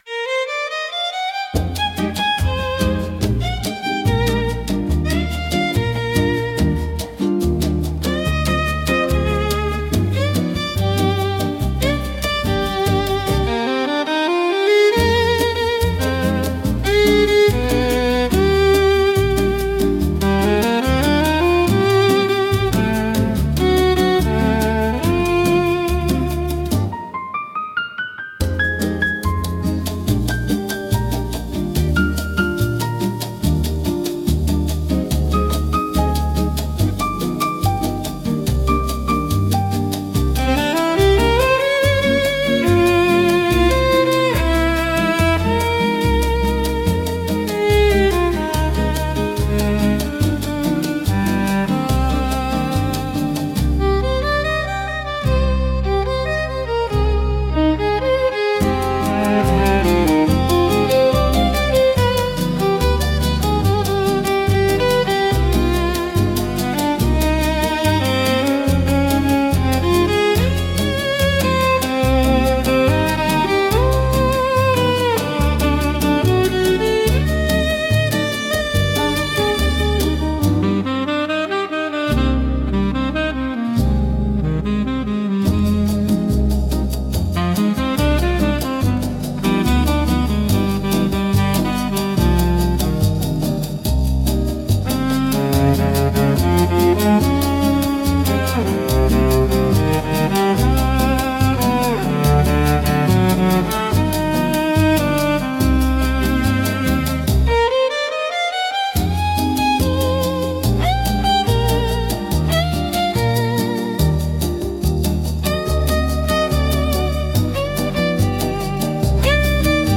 música e arranjo IA) instrumental 6